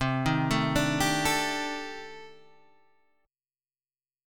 Cm6add9 chord {8 6 7 7 8 5} chord